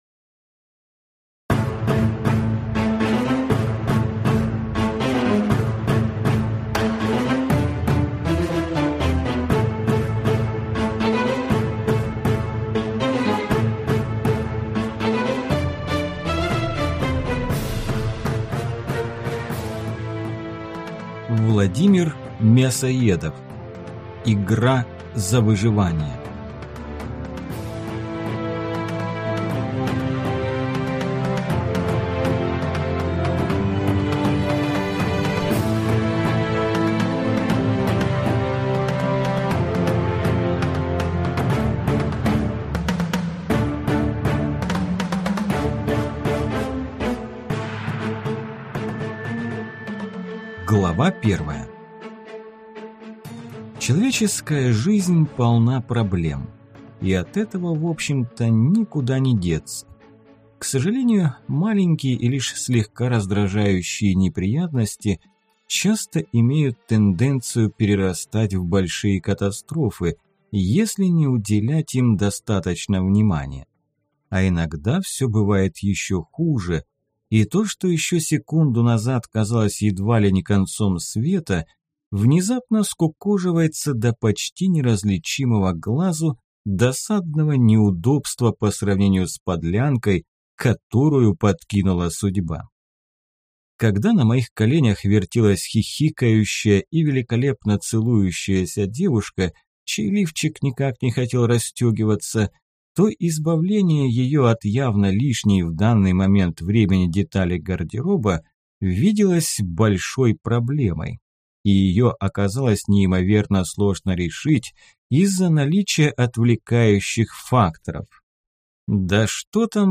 Aудиокнига Игра за выживание